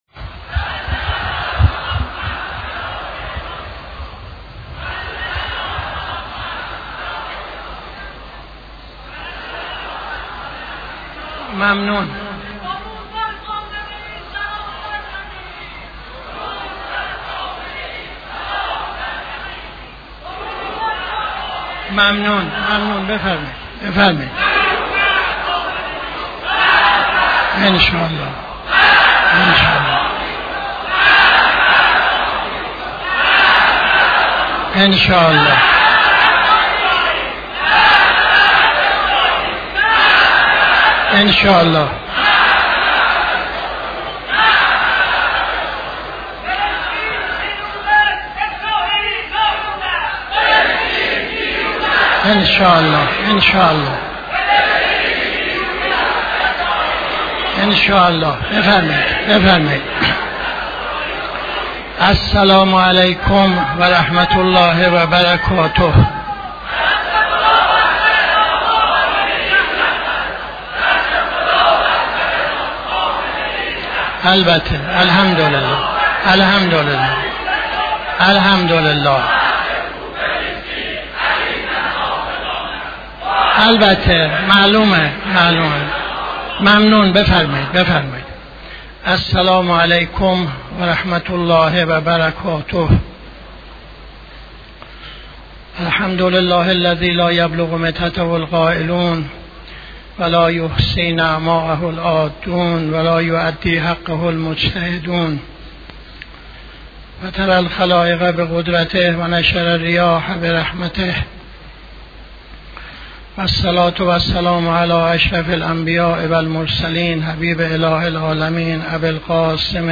خطبه اول نماز جمعه 07-02-80